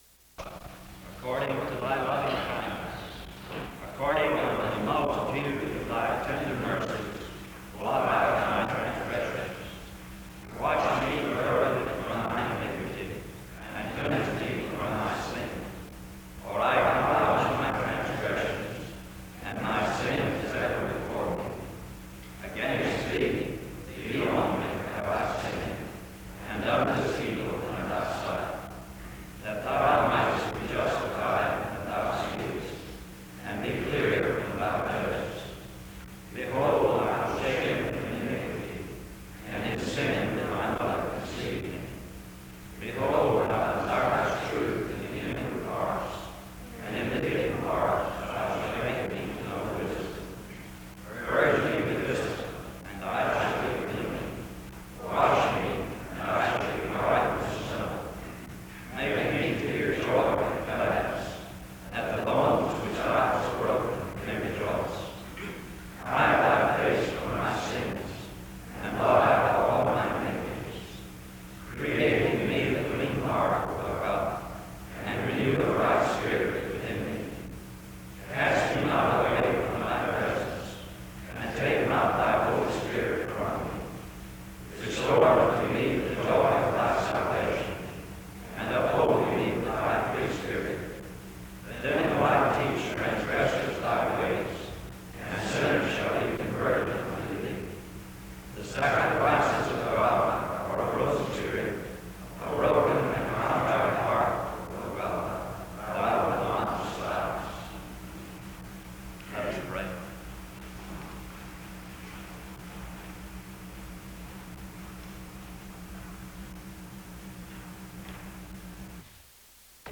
The service starts with an opening scripture reading from 0:00-1:49. A prayer is offered from 1:50-2:11.
SEBTS Chapel and Special Event Recordings